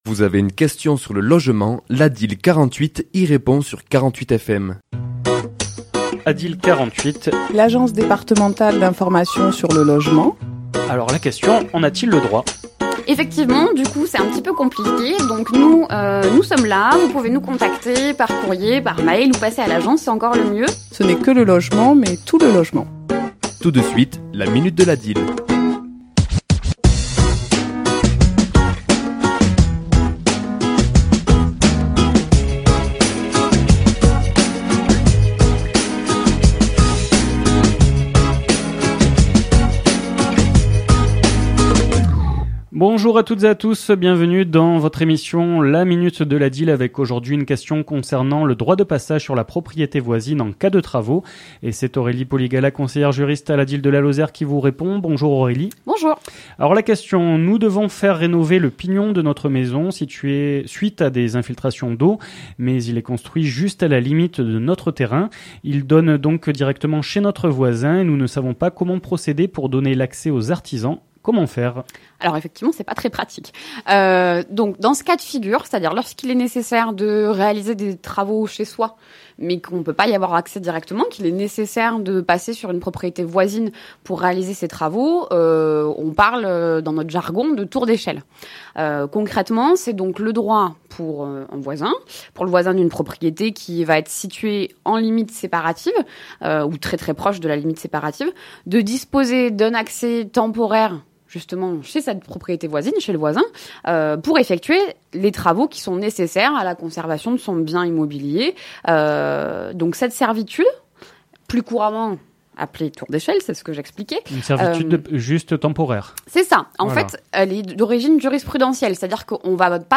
Chronique diffusée le mardi 16 et le jeudi 18 mai à 11h00 et 17h45,